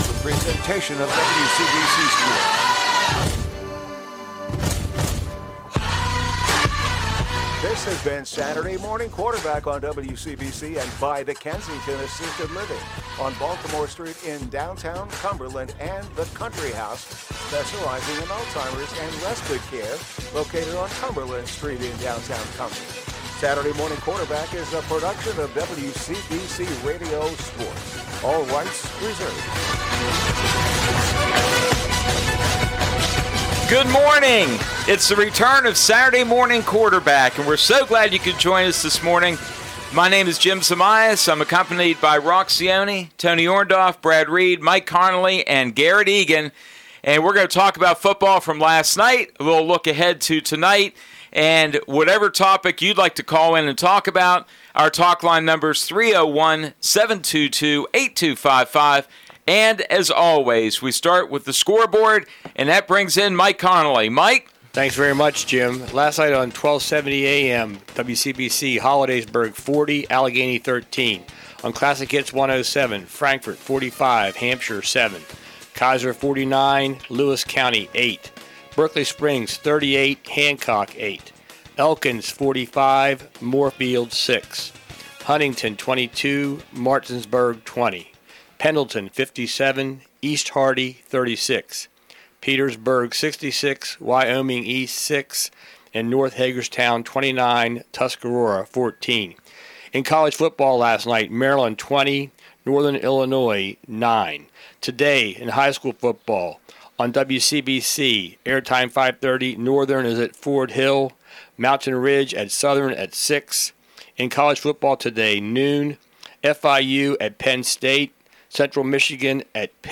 Video of today's broadcast.